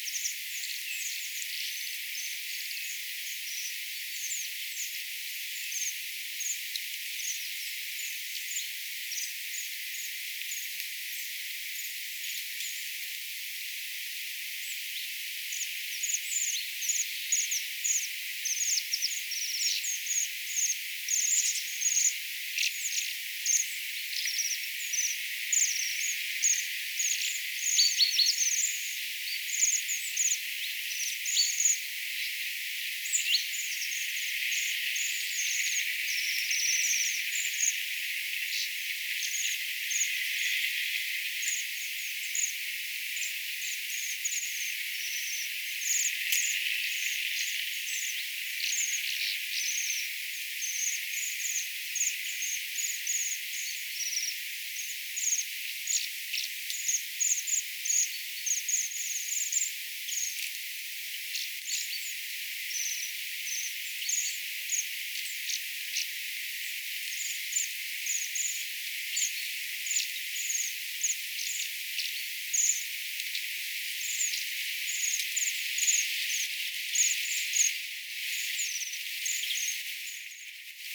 tilhien "rahinoita"
tilhien_erilaisia_aania_rahinoita.mp3